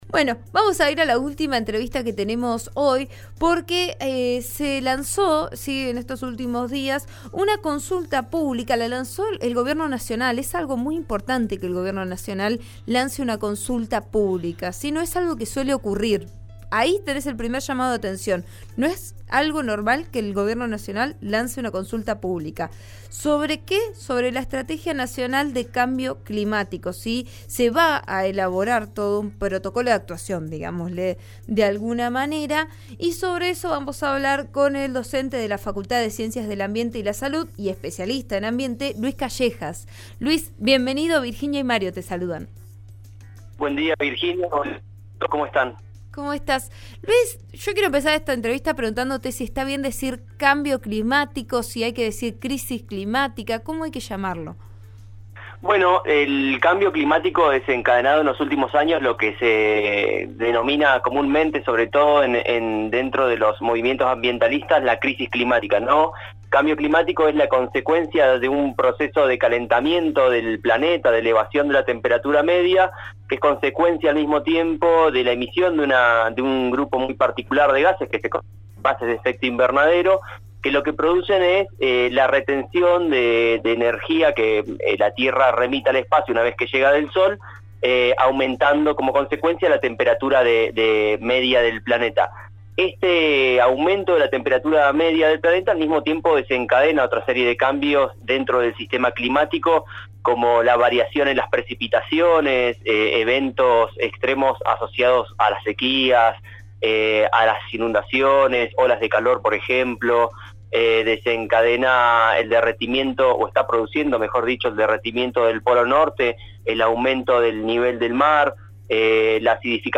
El especialista en ambiente